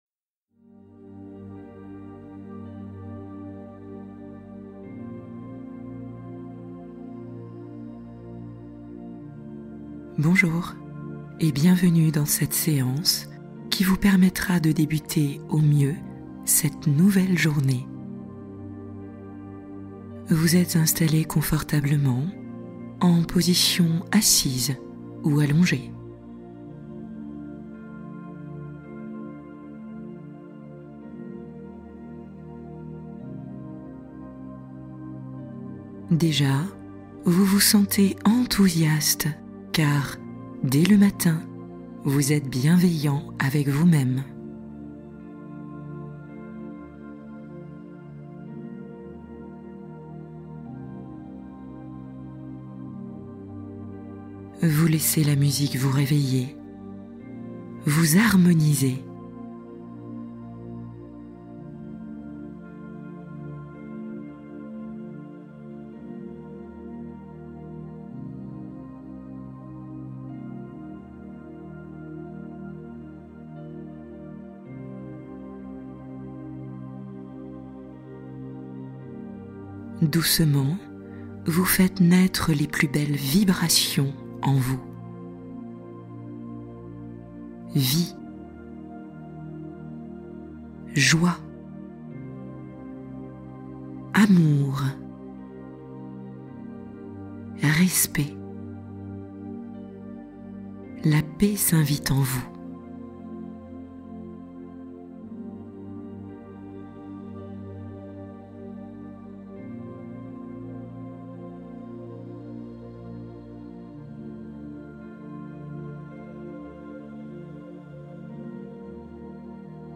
Méditation du soir pour enfants et parents : s’endormir dans la forêt magique